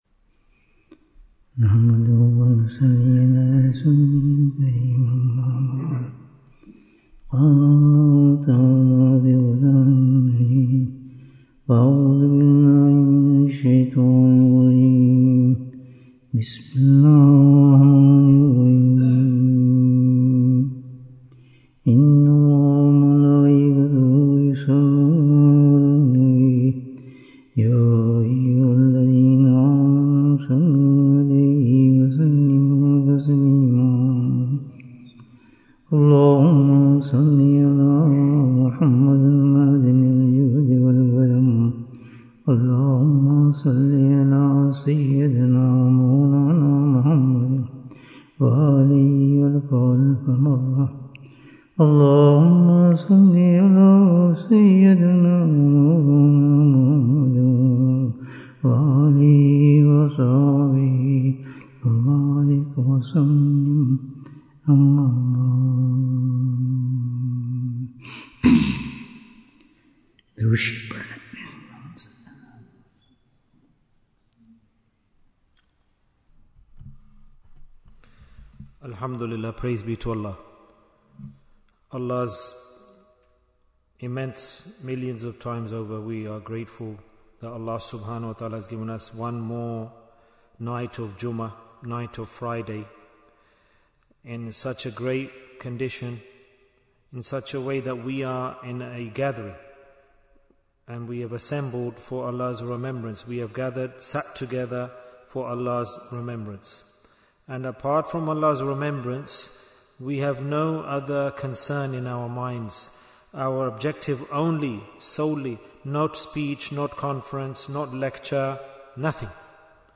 Way of Reform Bayan, 75 minutes30th October, 2025